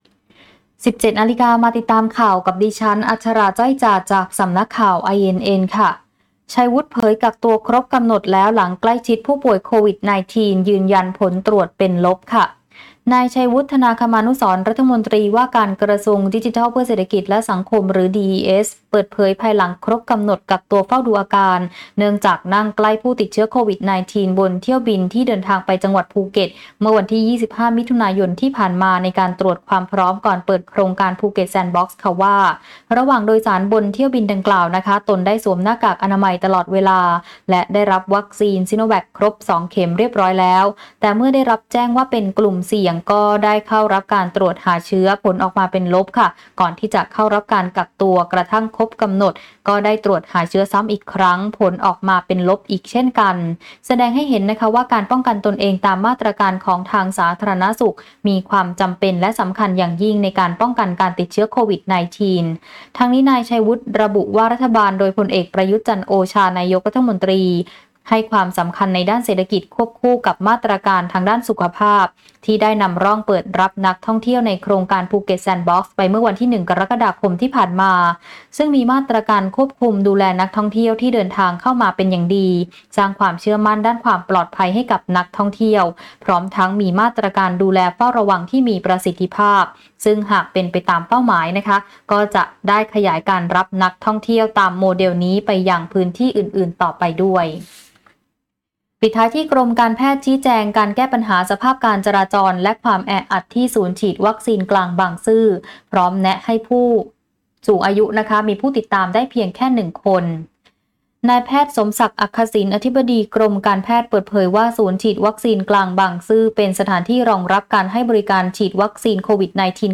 คลิปข่าวต้นชั่วโมง
ข่าวต้นชั่วโมง 17.00 น.